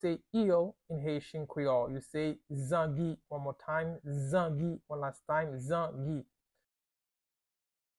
Listen to and watch “Zangi” audio pronunciation in Haitian Creole by a native Haitian  in the video below:
Eel-in-Haitian-Creole-Zangi-pronunciation-by-a-Haitian-teacher.mp3